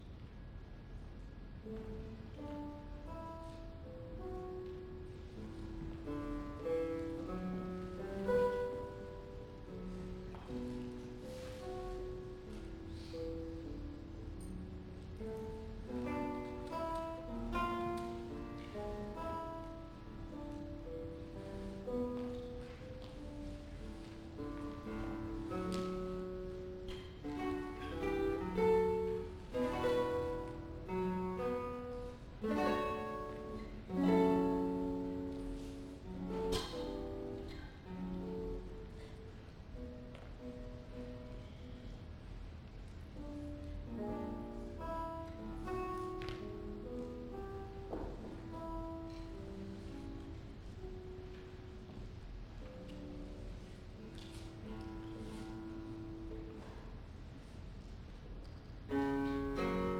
1992年12月18日　於 市民プラザ アンサンブルホール
独奏2